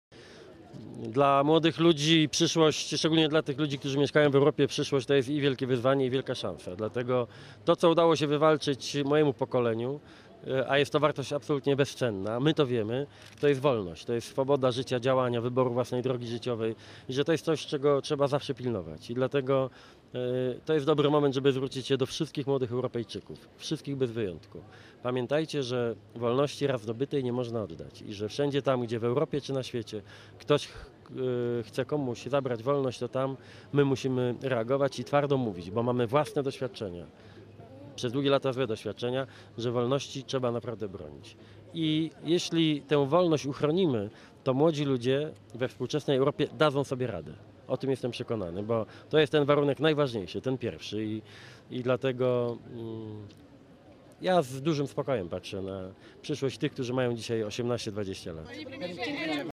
Specjalne wystąpienie
do młodych liderów w Nowym Sączu